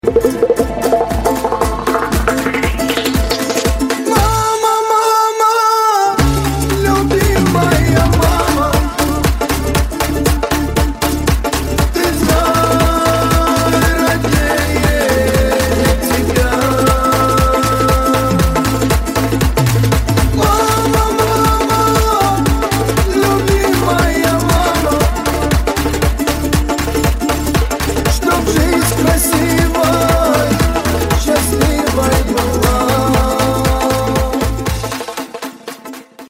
Кавказские Рингтоны
Шансон Рингтоны